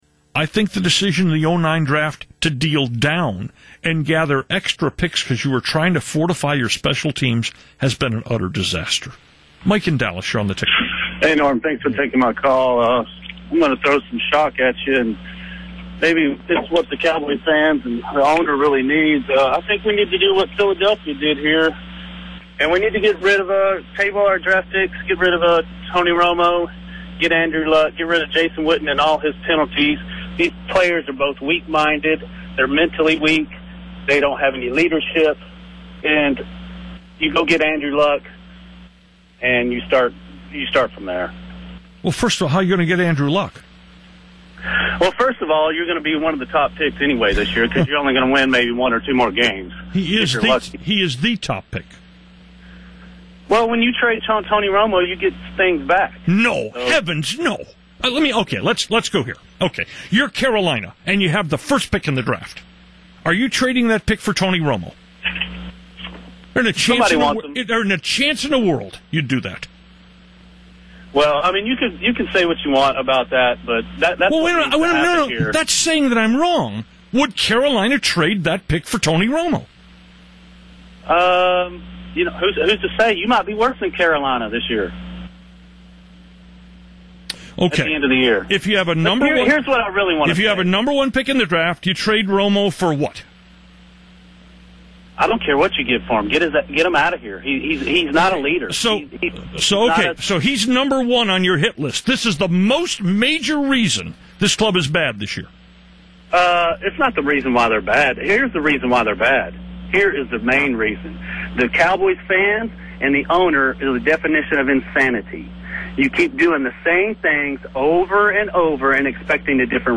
Everyone loves it with Norm twists off on a caller. This particular instigator/victim tried to wind Norm up by suggesting that the Cowboys trade away Romo and Witten for the overall pick in next year’s draft.